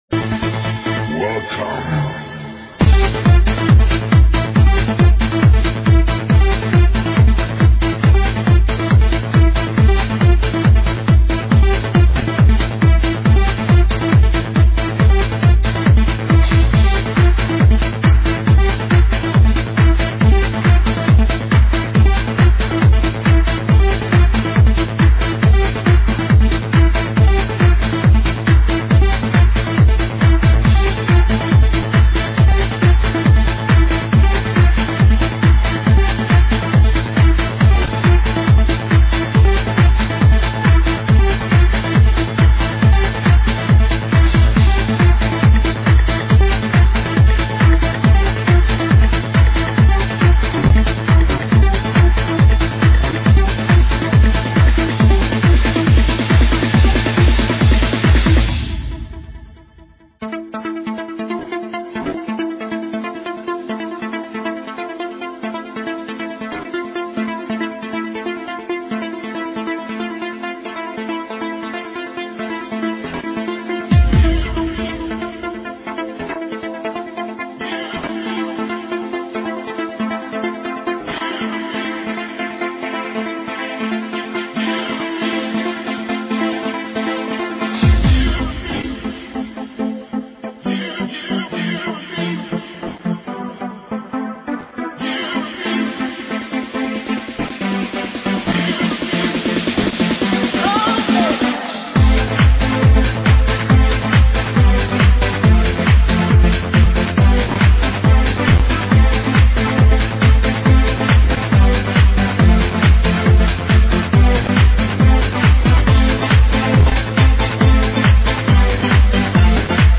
epic trance tune long forgotten